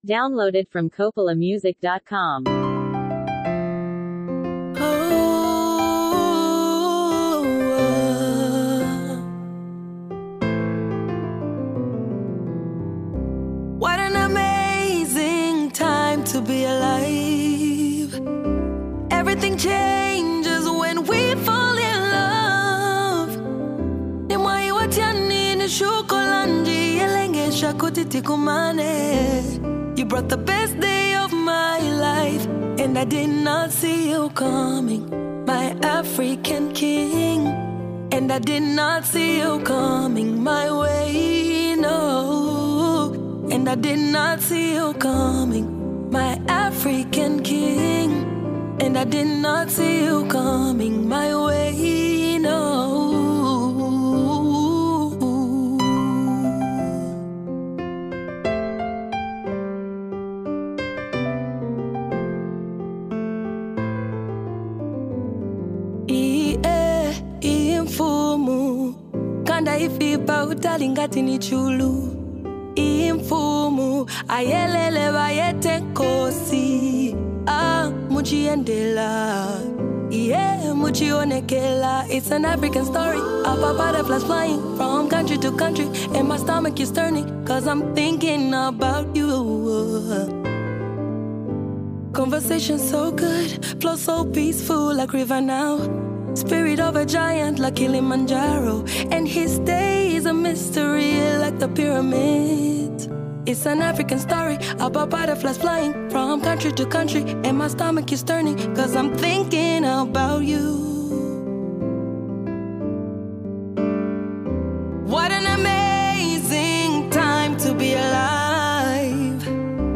powerful and uplifting song
With her soulful voice and heartfelt lyrics
love song